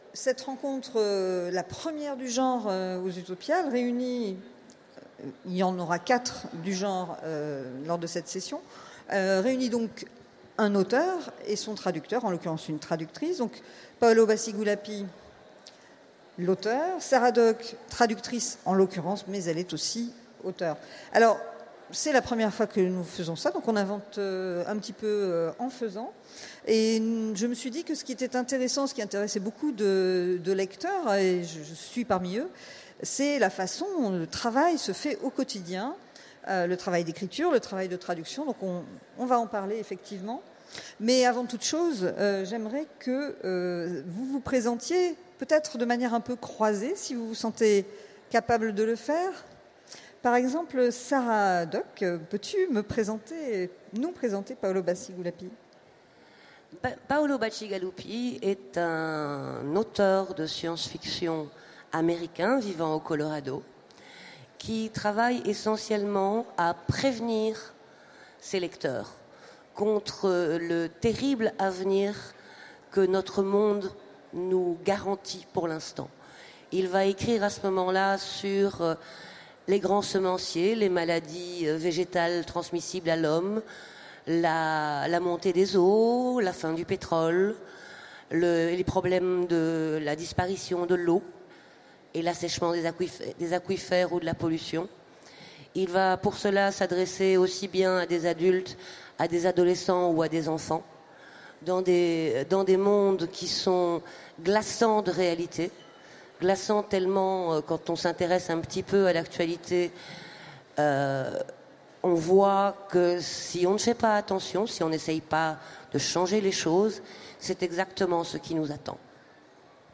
Utopiales 2016 : Conférence L'auteur et son ombre
Conférence